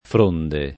fronda [fr1nda] s. f. («ramoscello; fogliame») — latinismo poet. fronde [